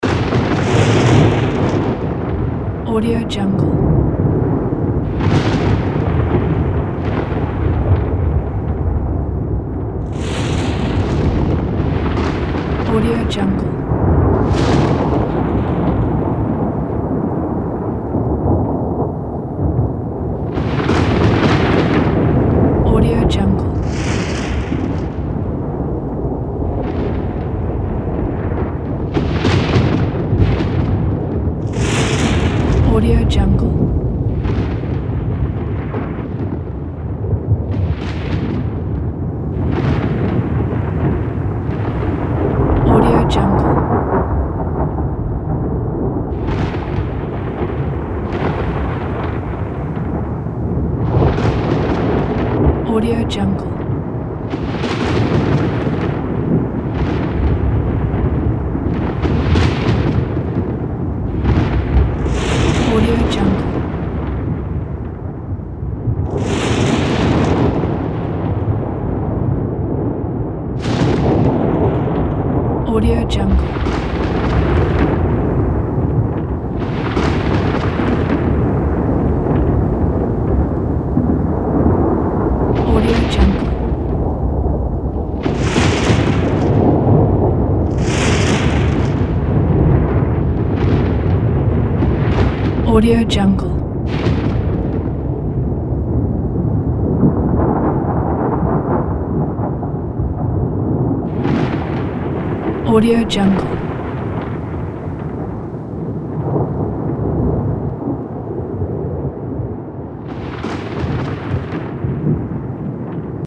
دانلود افکت صدای آمبیانس رعد و برق
افکت صدای آمبیانس رعد و برق یک گزینه عالی برای هر پروژه ای است که به صداهای طبیعت و جنبه های دیگر مانند رعد و برق، صاعقه و تندر نیاز دارد.
Sample rate 16-Bit Stereo, 44.1 kHz
Looped Yes